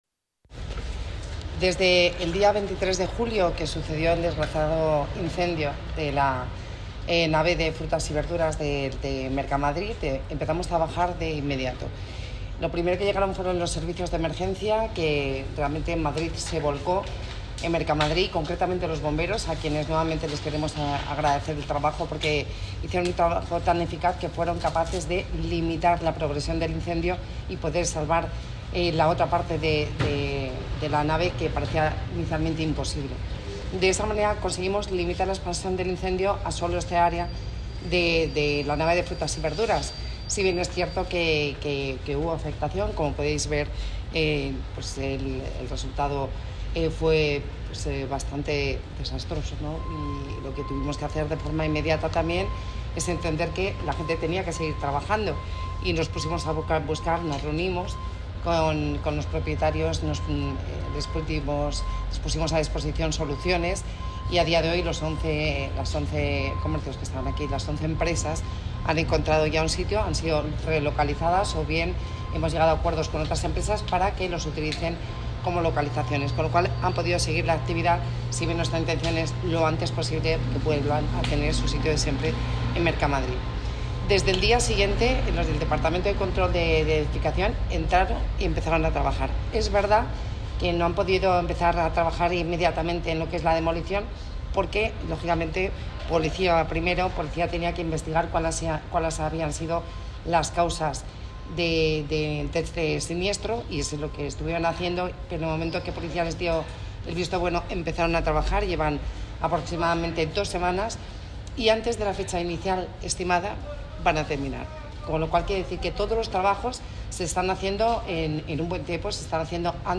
Nueva ventana:Intervención de la vicealcaldesa de Madrid, Begoña Villacís